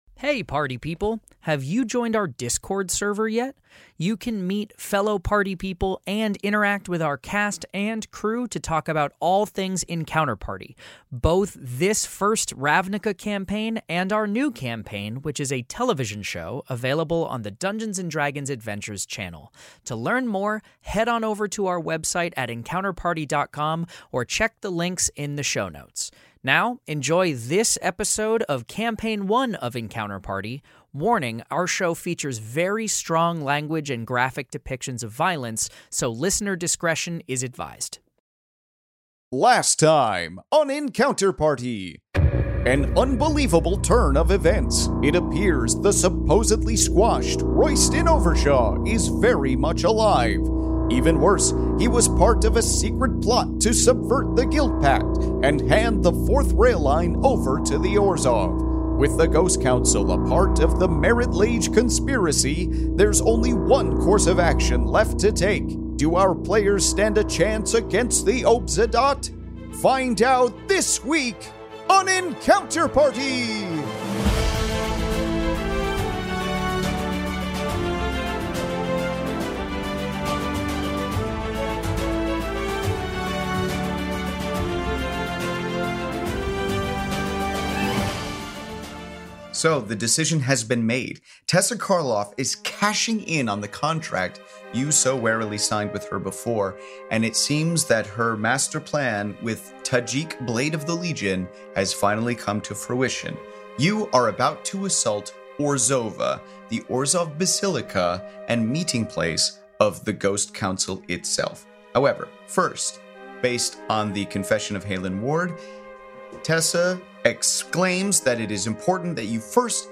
Fantasy Mystery Audio Adventure
leads five actors and comedians through an epic tale of action, adventure, and intrigue!